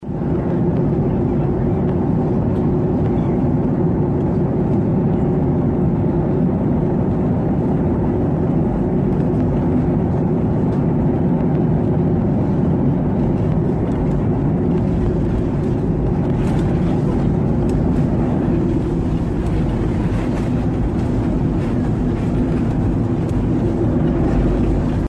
Shaking Plane Botão de Som
Sound Effects Soundboard0 views